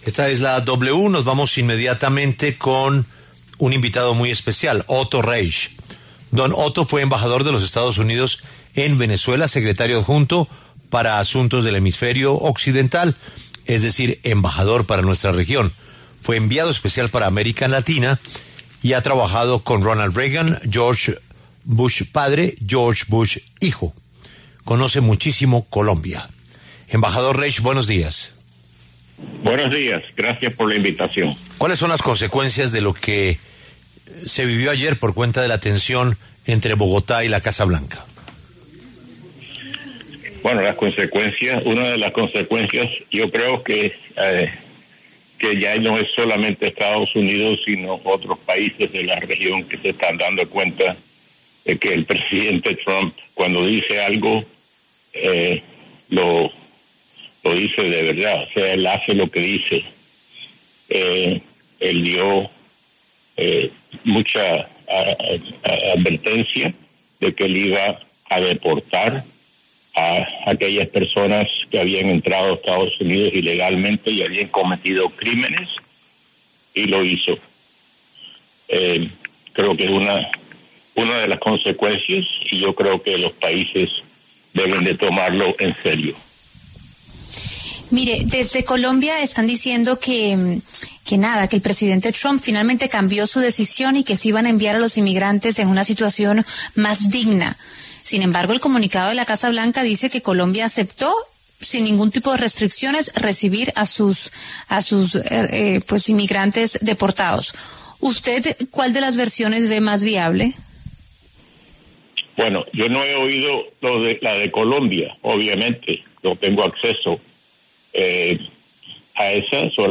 En diálogo con La W, el exembajador de Estados Unidos les recomendó a los países de la región tomar en serio las palabras de Donald Trump.
Otto Reich, exembajador de Estados Unidos en Venezuela, conversó con La W, con Julio Sánchez Cristo, sobre la tensión en las relaciones diplomáticas y comerciales entre Colombia y EE.UU.